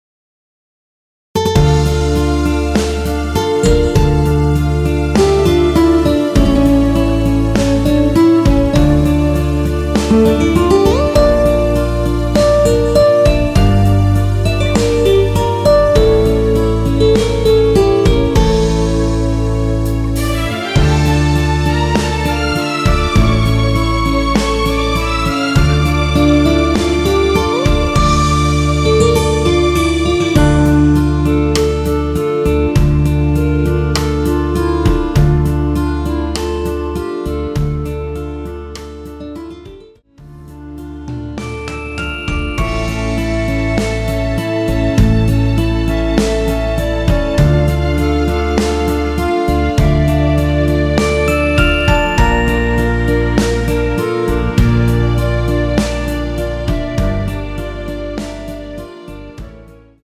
원키 멜로디 포함된 MR입니다.
Dm
앞부분30초, 뒷부분30초씩 편집해서 올려 드리고 있습니다.
중간에 음이 끈어지고 다시 나오는 이유는